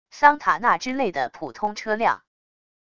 桑塔纳之类的普通车辆wav音频